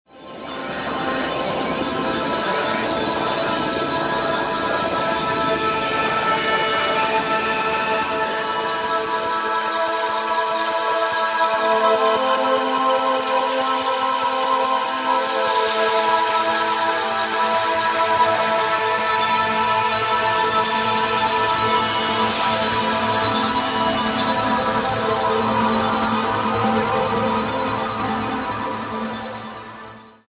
begins with typical WS atmospheric evolving pads: